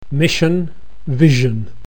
missionvision.mp3